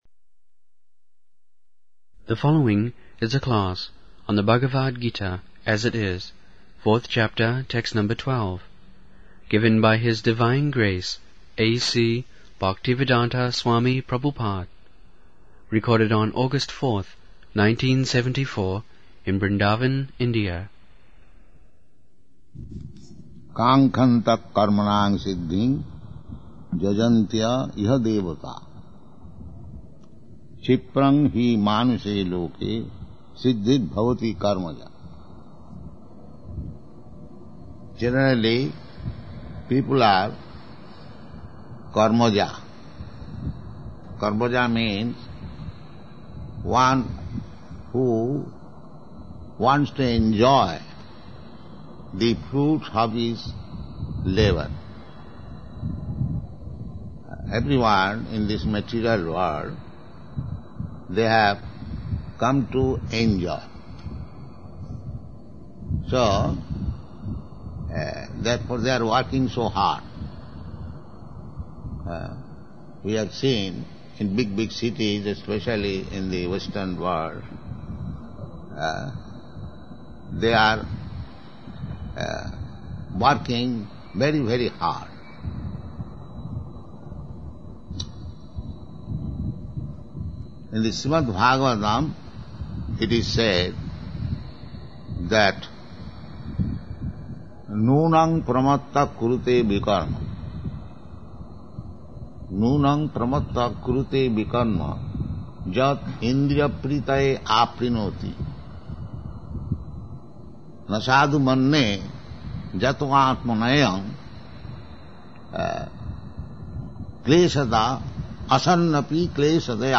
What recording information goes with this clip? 74/04/02 Bombay, Bhagavad-gita 4.13 Listen